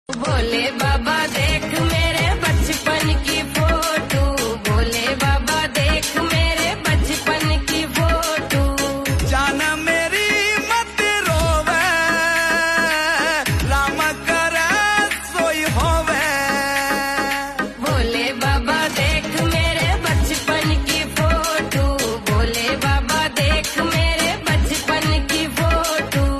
Rajasthani song